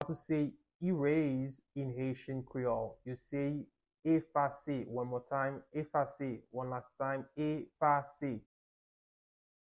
Pronunciation:
7.How-to-say-Erase-in-Haitian-Creole-–-efase-with-Pronunciation.mp3